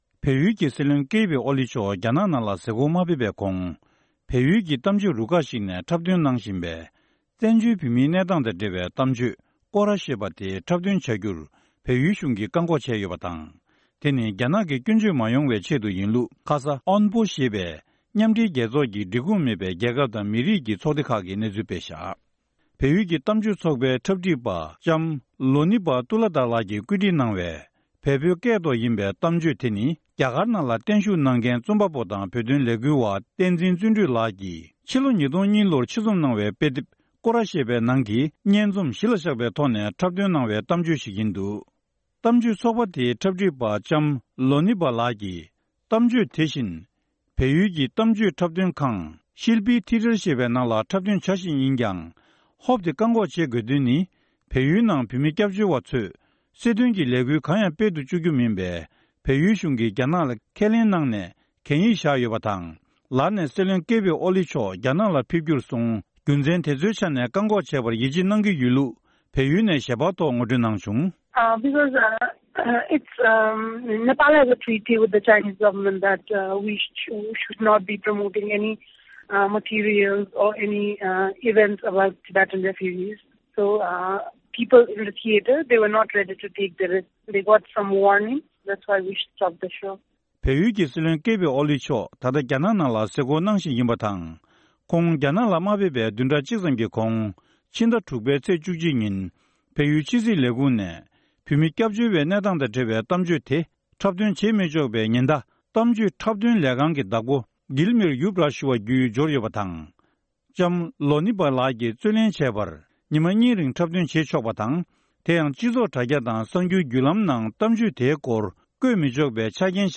བཀའ་འདྲི་ཞུས་ནས་ཕྱོགས་སྒྲིགས་དང་སྙན་སྒྲོན་ཞུས་པར་གསན་རོགས་ཞུ